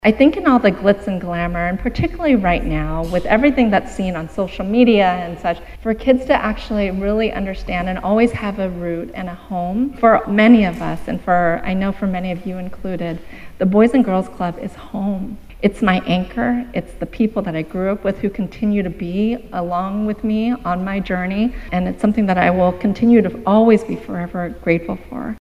Bartlesville and Dewey Youth of the Year luncheon
Friday at the club location in Bartlesville.